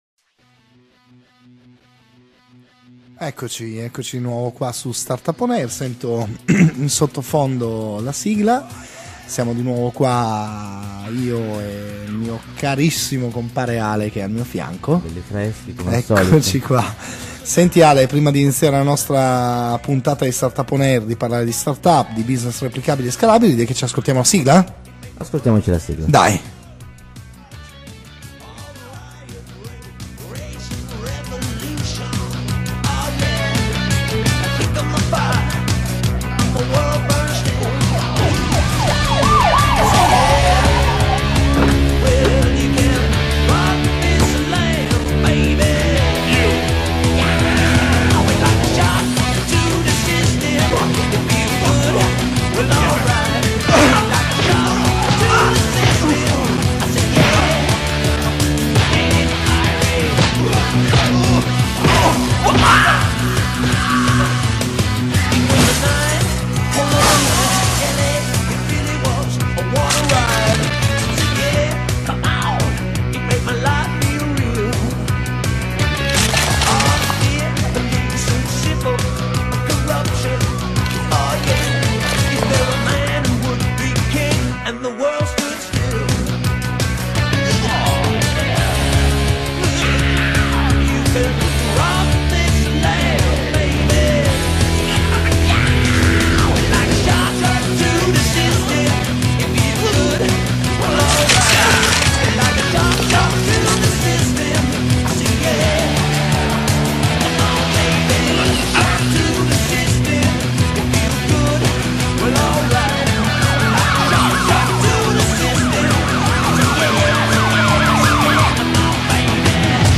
StartupOnAir Sedicesima puntata – Intervista